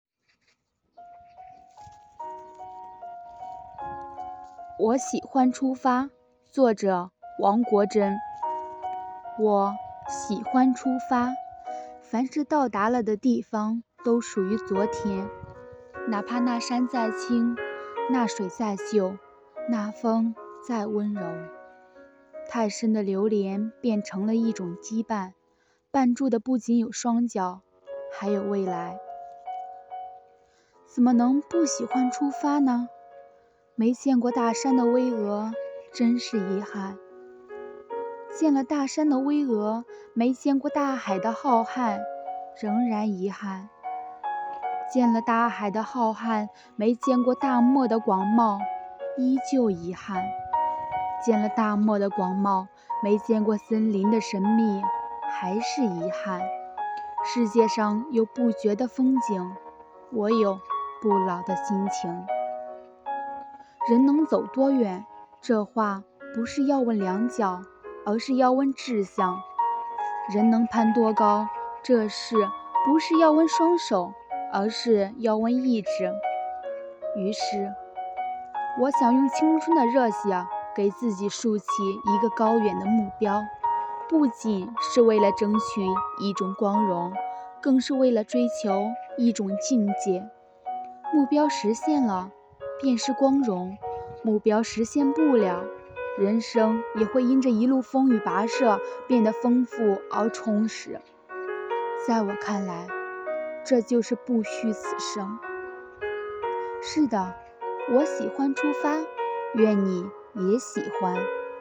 “阅读的力量 -- 读给你听”主题朗诵